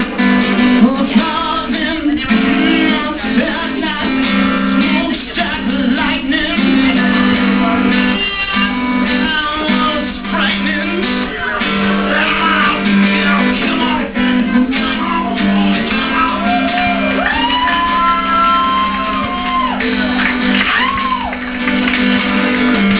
LIVE!!